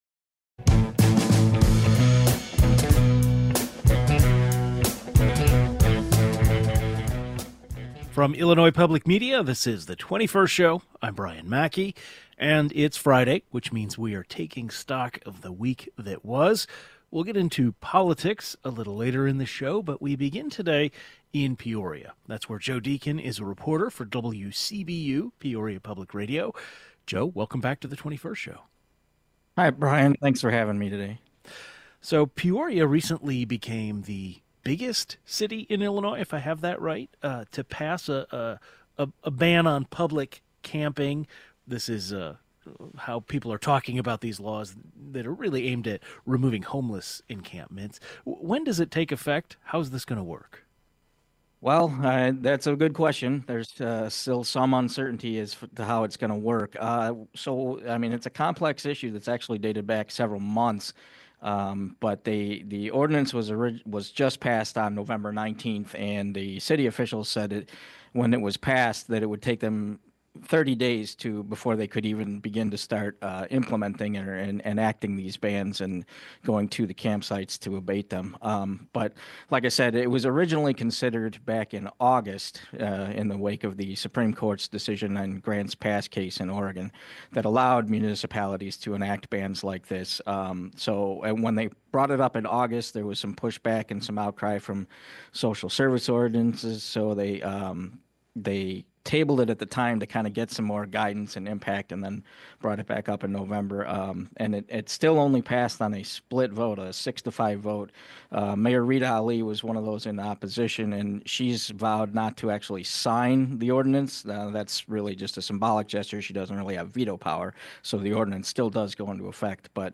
This week for our weekly roundup of Illinois news, we focus on Peoria. A public radio reporter joins the 21st to discuss the city's recent passing of a public camping ban, what concerns the LGBTQ community residing in Illinois may have as President-elect Donald Trump returns to power, as well as gas prices and a proposed go-kart venue for Peoria.